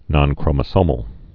(nŏnkrō-mə-sōməl)